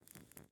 Minecraft Version Minecraft Version snapshot Latest Release | Latest Snapshot snapshot / assets / minecraft / sounds / block / candle / ambient8.ogg Compare With Compare With Latest Release | Latest Snapshot